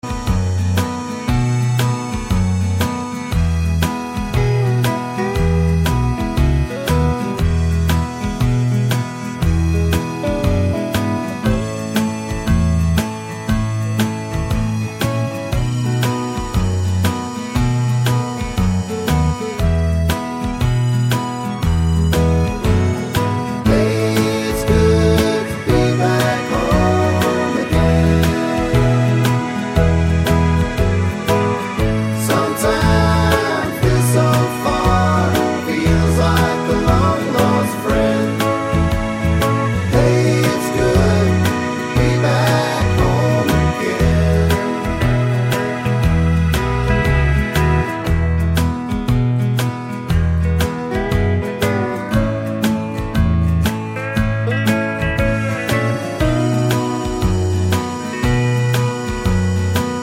no Backing Vocals Country (Male) 4:42 Buy £1.50